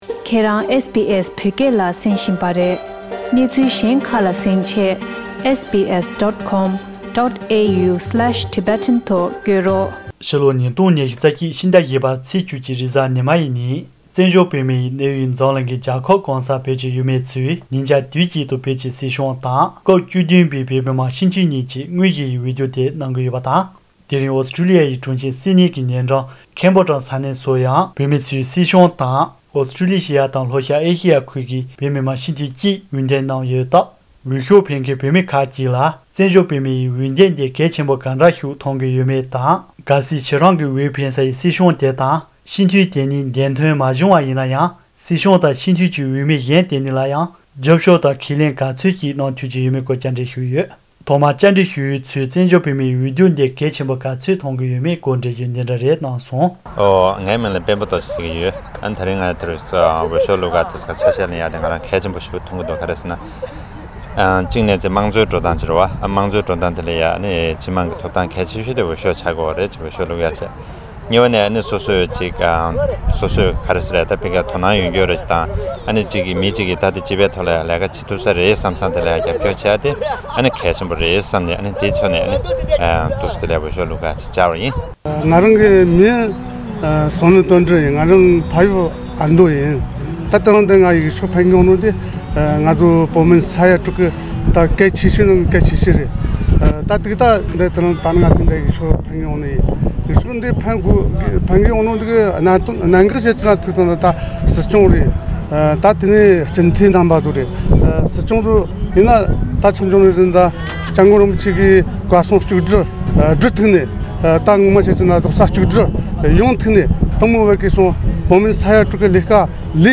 ཨོ་སི་ཊོ་ལི་ཡའི་གྲོང་ཁྱེར་སིཌ་ནིང་གི་ཉེ་འགྲམ་ཁེམ་བྷོ་ཊམ་ས་གནས་སུ་འོས་ཤོག་འཕེན་མཁན་བོད་མི་ཁག་ཅིག་ལ་བཙན་བྱོལ་བོད་མིའི་འོས་འདེམས་འདི་གལ་ཆེན་པོ་གང་འདྲ་ཞིག་མཐོང་གི་ཡོད་མེད་དང་། གལ་སྲིད་ཁྱེད་རང་གི་འོས་འཕེན་སའི་སྲིད་སྐྱོང་དེ་དང་སྤྱི་འཐུས་དེ་གཉིས་འདེམས་ཐོན་མ་བྱུང་བ་ཡིན་ན་ཡང་། སྲིད་སྐྱོང་དང་སྤྱི་འཐུས་ཀྱི་འོས་མི་གཞན་དེ་གཉིས་ལ་རྒྱབ་སྐྱོར་དང་ཁས་ལེན་ག་ཚོད་ཅིག་གནང་ཐུབ་ཀྱི་ཡོད་མེད་བཅར་འདྲི་ཞུས་ཡོད།